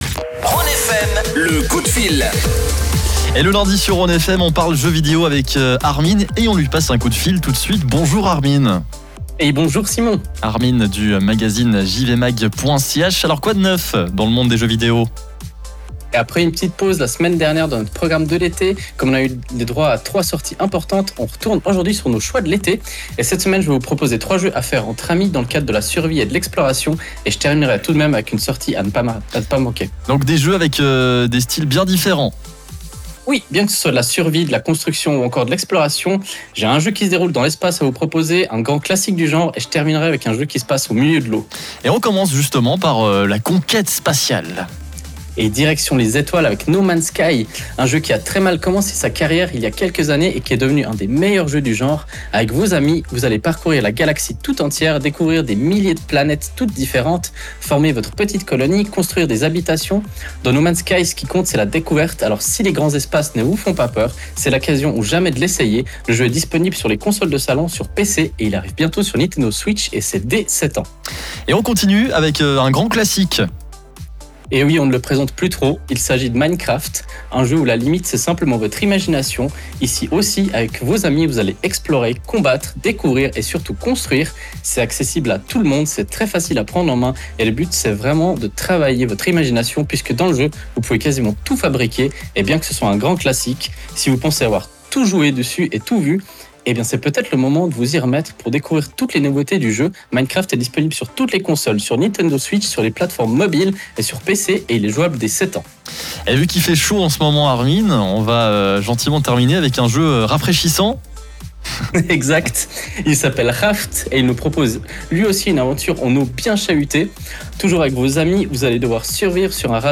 Avec les beaux jours qui continuent d’affluer, c’est le moment de vous proposer des jeux à parcourir avec vos amis. Ce n’est pas tout, on vous annonce aussi la grosse sortie de la semaine. Le direct est à réécouter juste au dessus.